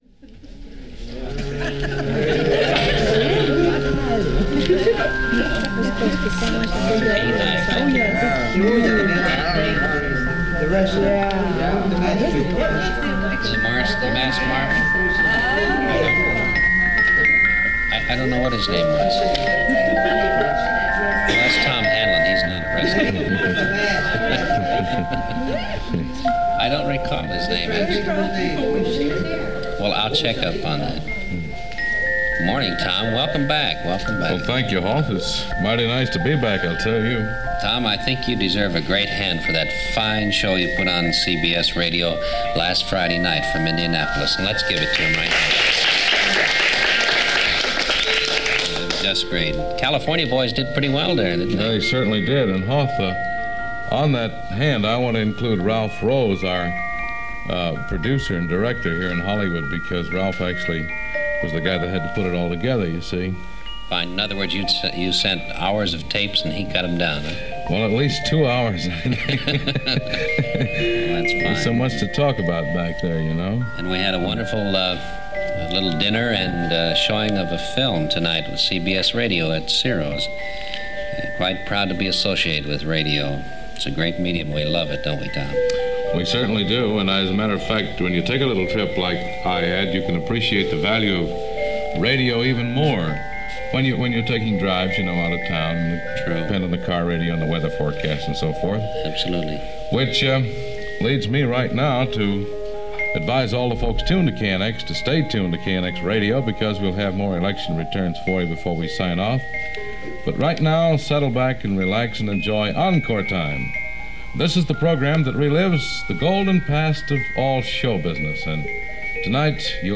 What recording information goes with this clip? It was a simple format – an audience (whoever was around at midnight to applaud and laugh) an announcer and a permanent guest to talk about the music being played.